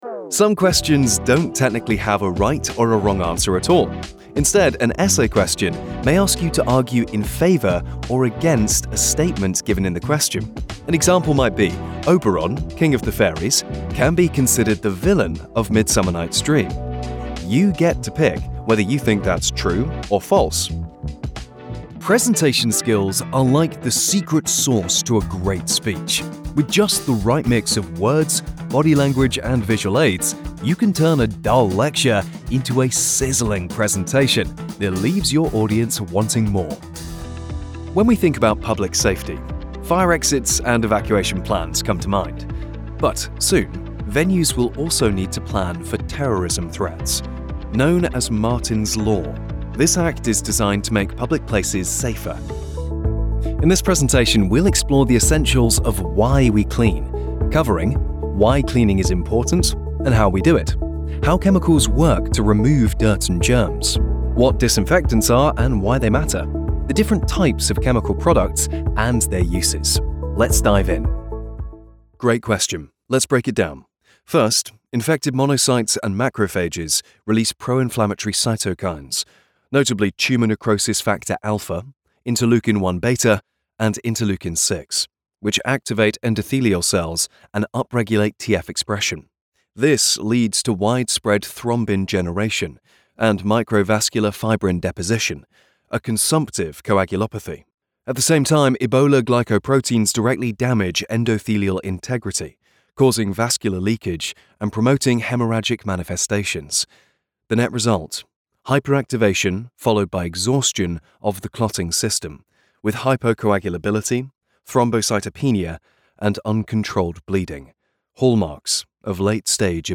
Englisch (Britisch)
Kommerziell, Natürlich, Freundlich, Warm, Vielseitig
E-learning